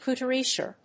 音節cou・tu・ri・ère 発音記号・読み方
/kuːtˈʊ(ə)riɚ(米国英語), kuːt`ʊəriéə(英国英語)/